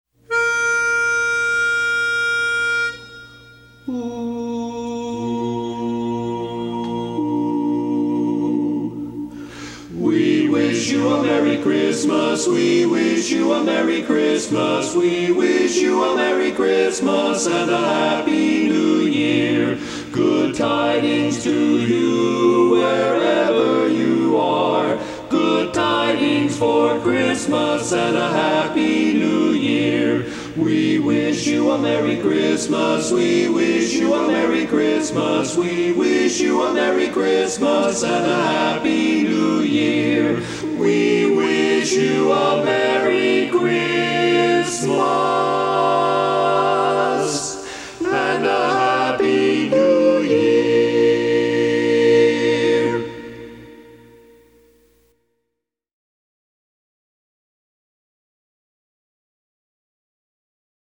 Christmas Songs
Barbershop